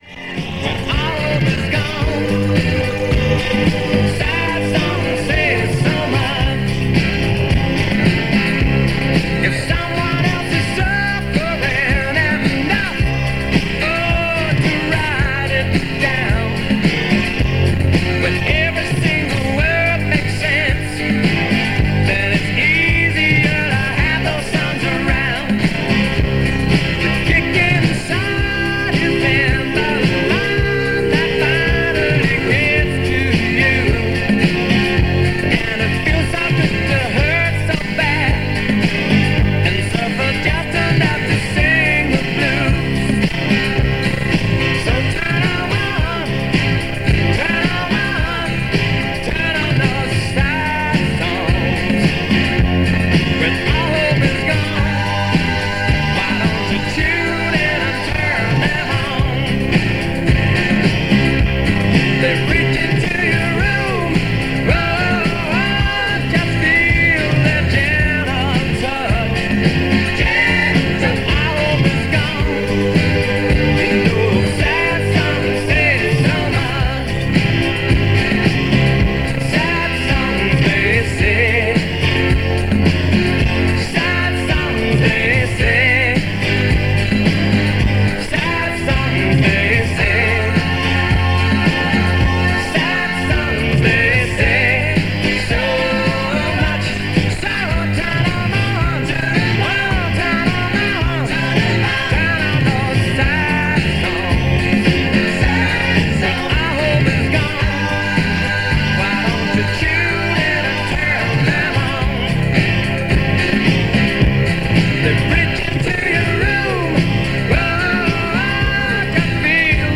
Despite the time of day, adverts are scarce and there are some technical issues with the music, so the station was clearly still finding its feet.